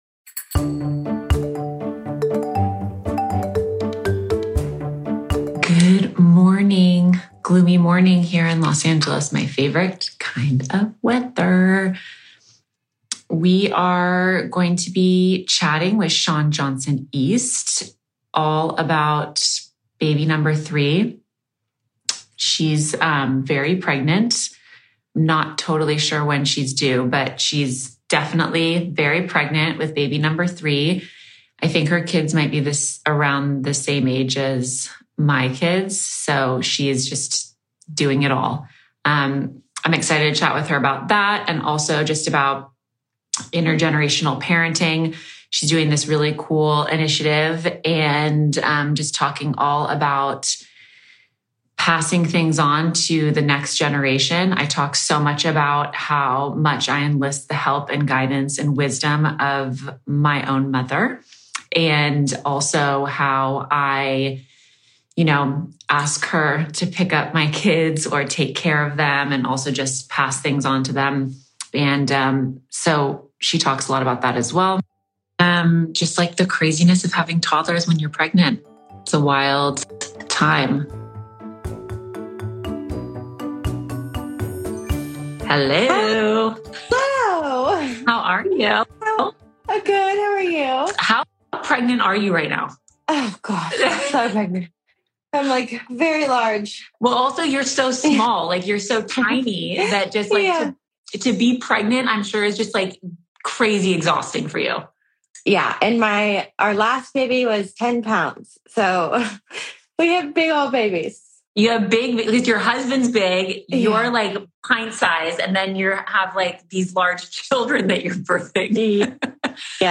We did this interview when Shawn was pregnant with her third baby so I asked her what it was like to share the exciting news with her two older children.